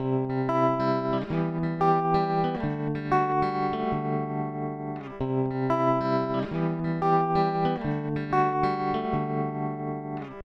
3. Trémolo
El trémolo consiste en la modulación del volumen de la guitarra.
Así suena mi guitarra aplicada con un trémolo:
guitarra-con-tremolo_i8cyml.ogg